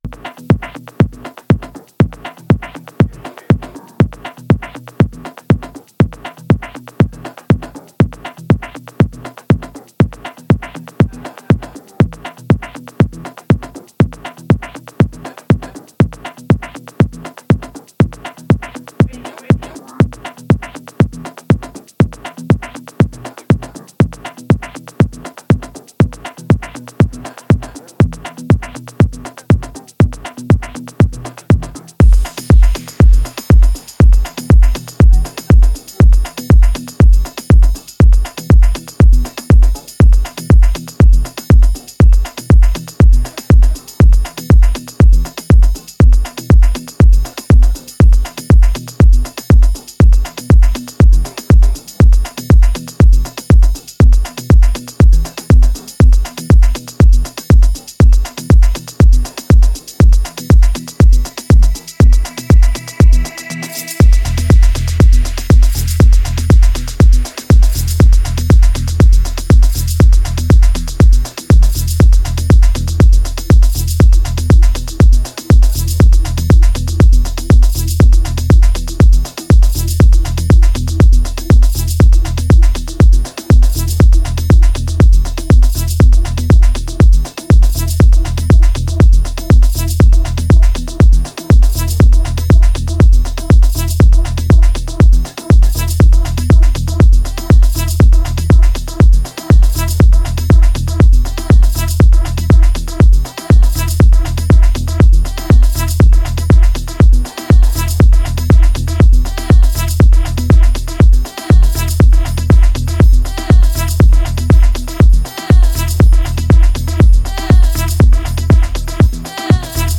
this nostalgic track blends House and Progressive House